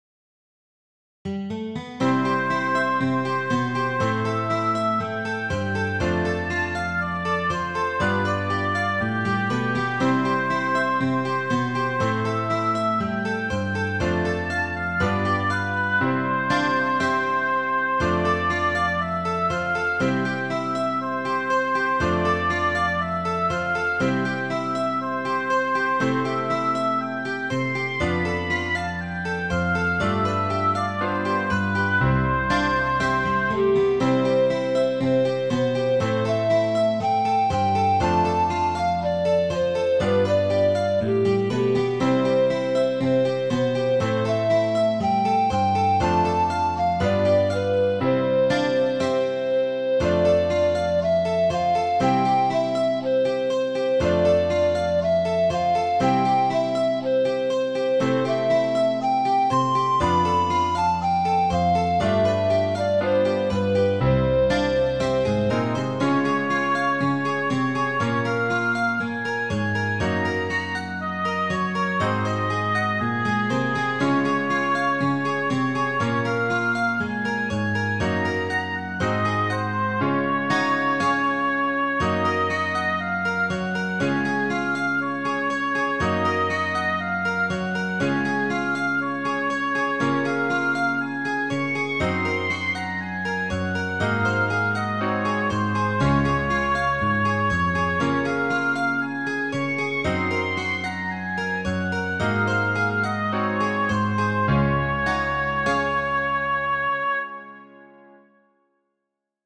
Midi File